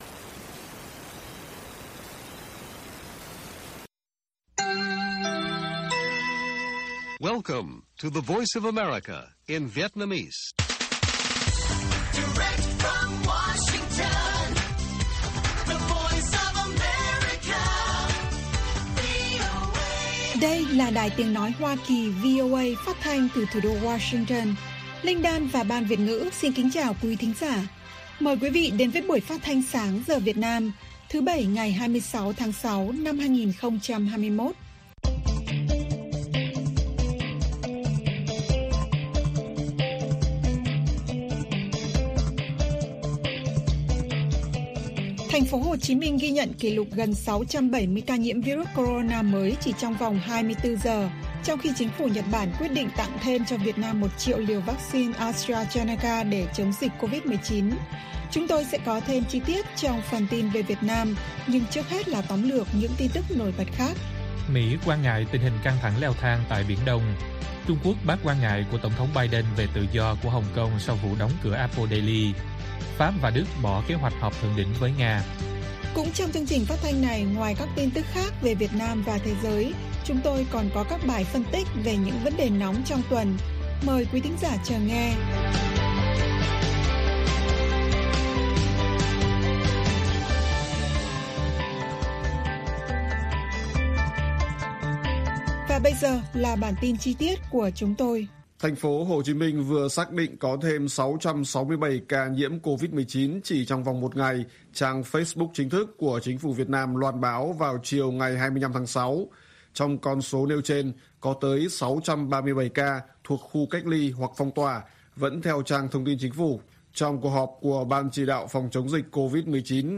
Bản tin VOA ngày 26/6/2021